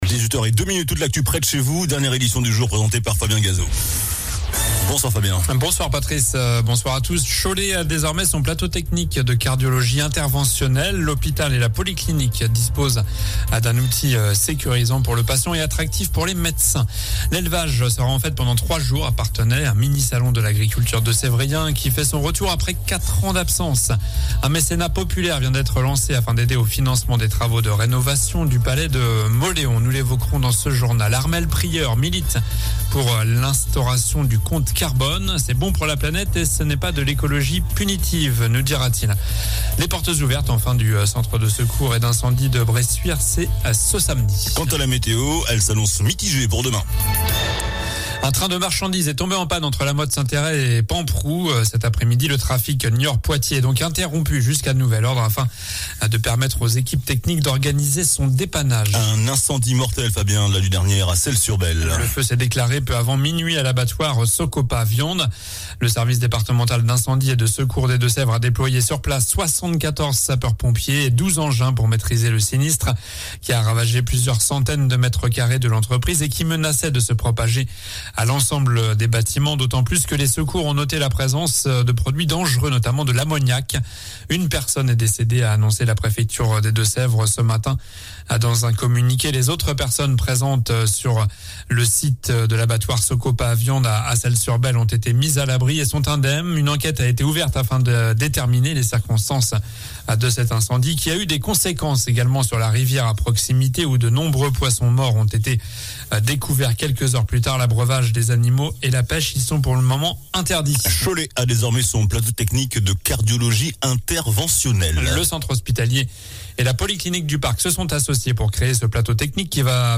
Journal du jeudi 21 septembre (soir)